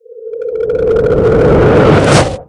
gravi_blowout2.ogg